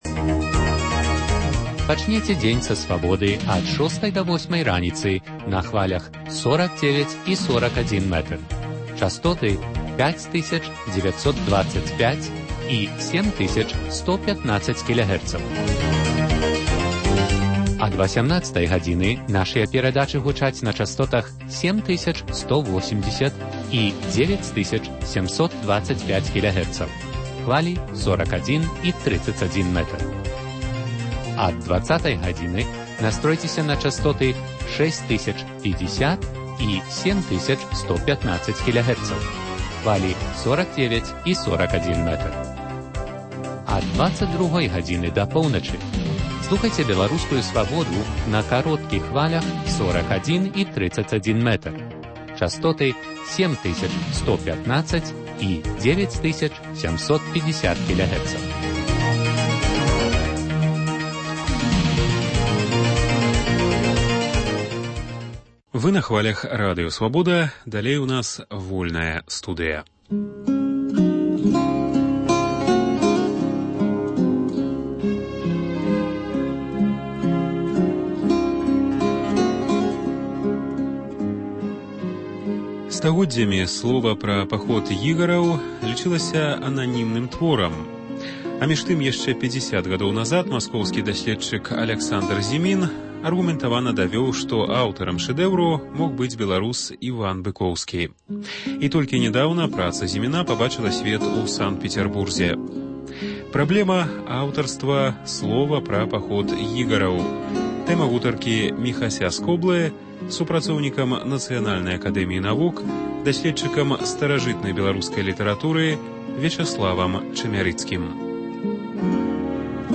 Праблема аўтарства “Слова пра паход Ігара” – тэма гутаркі